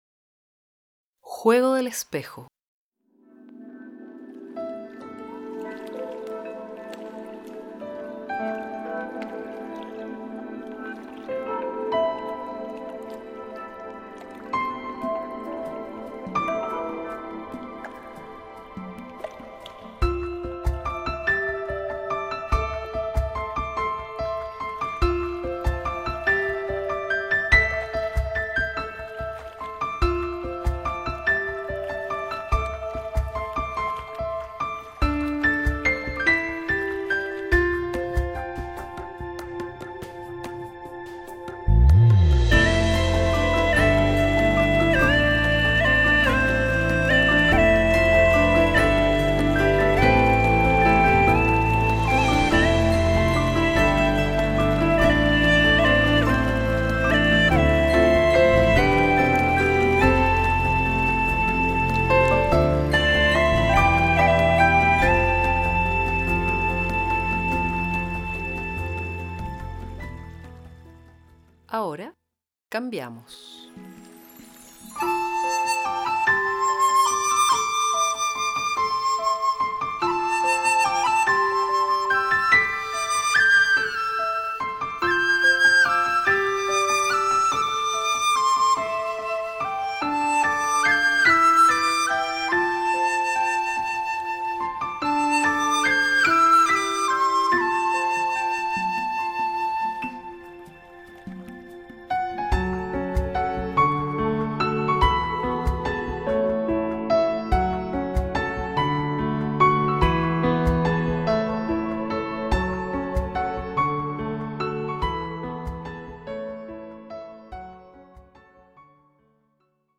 Melodía.